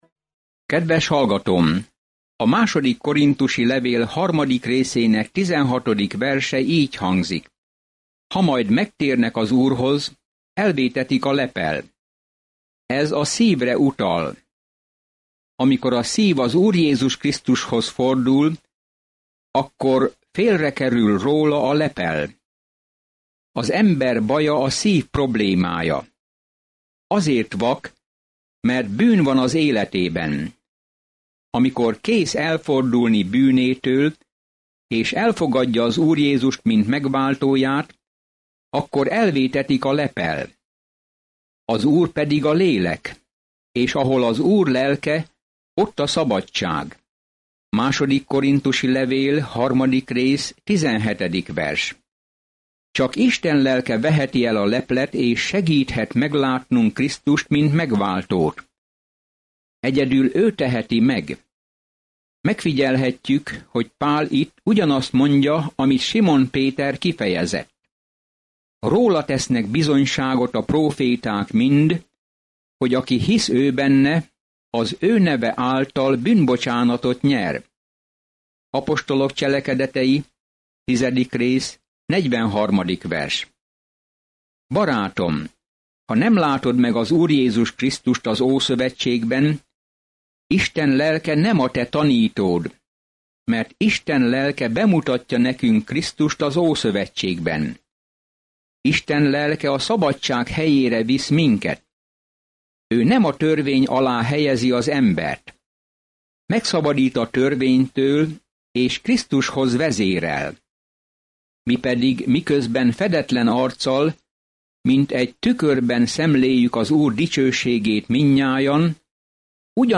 Napi utazás a 2 korinthusi levélben, miközben hallgatod a hangos tanulmányt, és olvasol válogatott verseket Isten szavából.